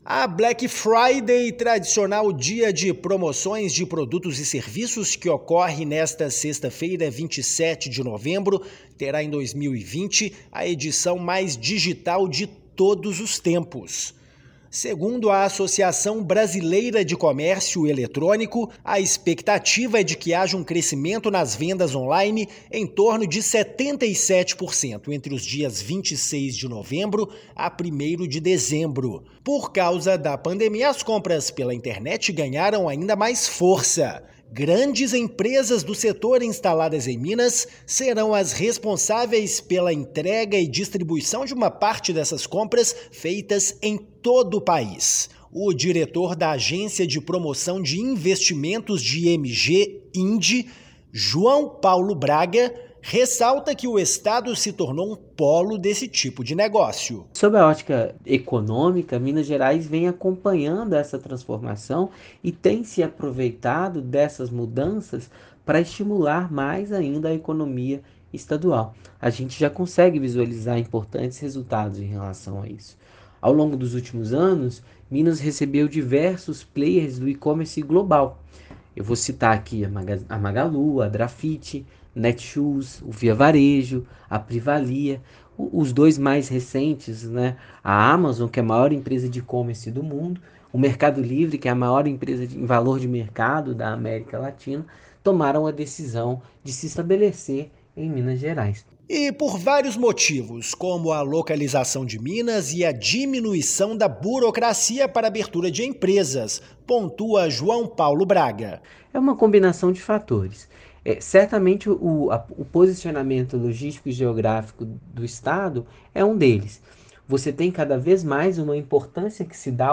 Estado vem consolidando como polo para instalação de grandes empresas do setor pela localização geográfica e diminuição da burocracia. Ouça a matéria de rádio.